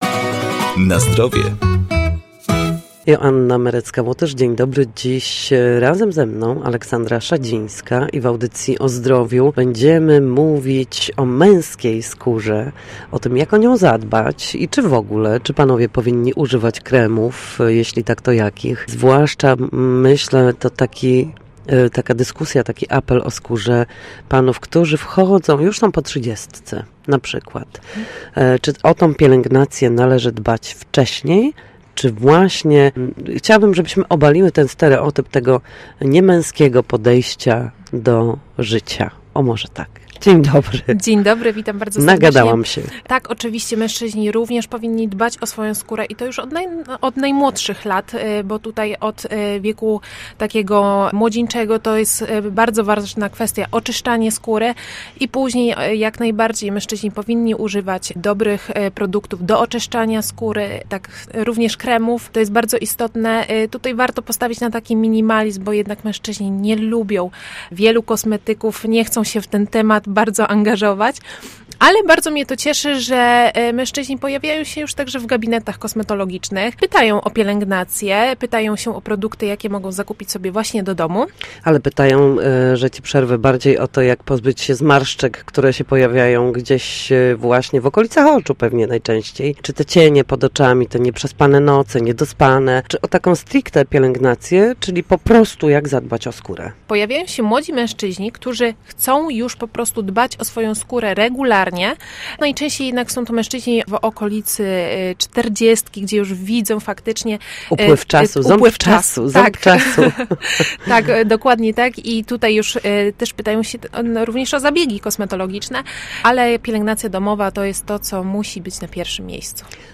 W poniedziałki o godzinie 7:20 a także po 14.30 na antenie Studia Słupsk przedstawiamy sposoby na powrót do formy po chorobach czy urazach. Rozkładamy na czynniki pierwsze wszelkie dolegliwości, także te, które psują nam urlop i radosne wakacje. Nasi goście, lekarze, fizjoterapeuci, w audycji „Na Zdrowie”, będą odpowiadać na pytania, dotyczące najczęstszych dolegliwości.